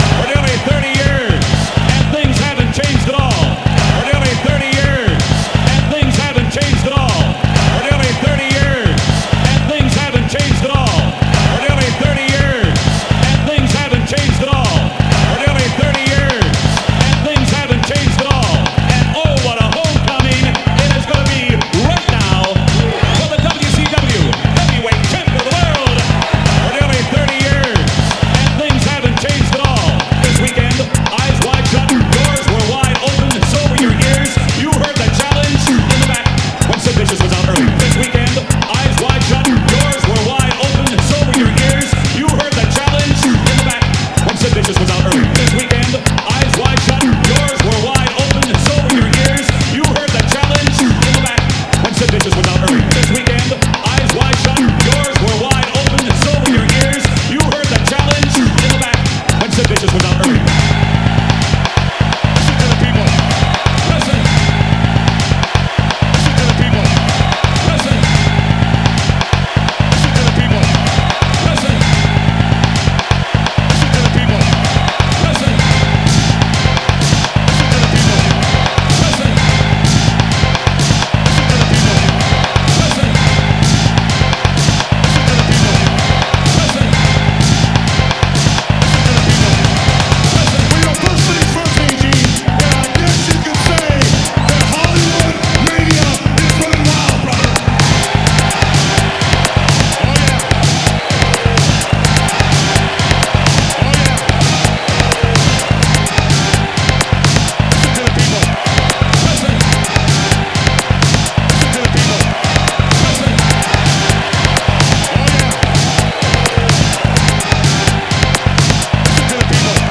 an Argentinian producer
Electro Techno Acid Wave